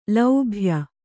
♪ laubhya